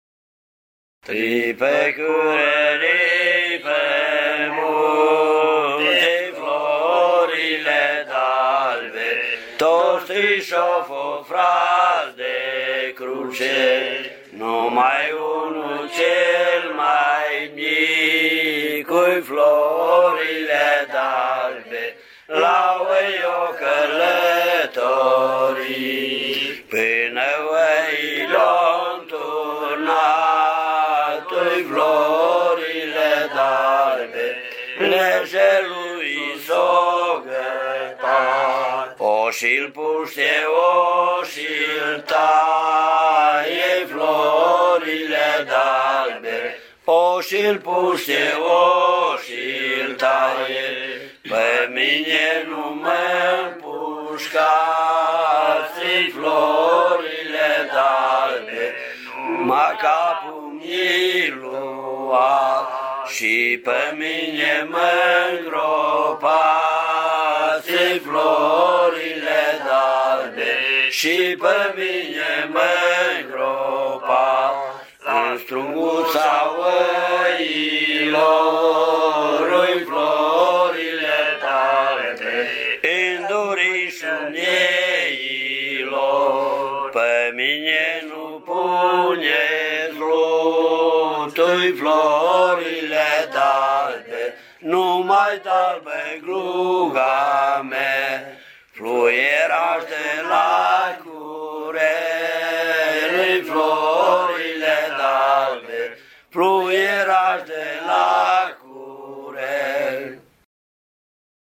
Colindă
Mălădia
Grup bărbați
Vocal